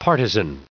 561_partisan.ogg